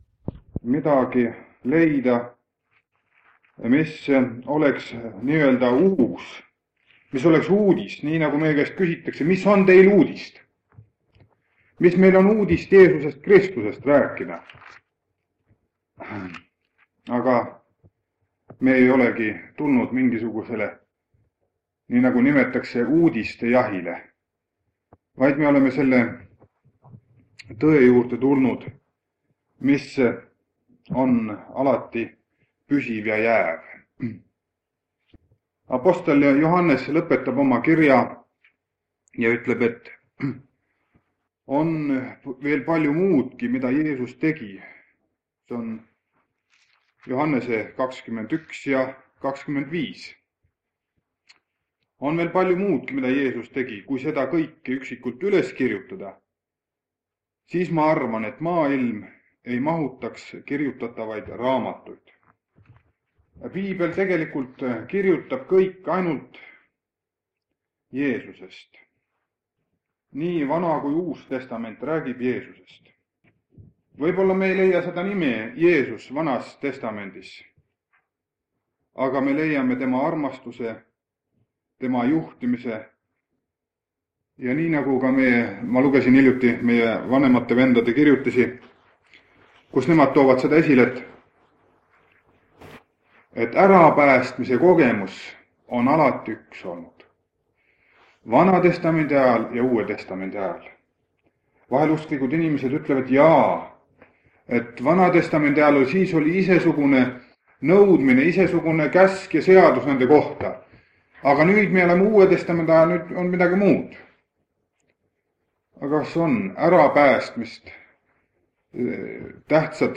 Jutlus vanalt lintmaki lindilt 1976 aastast.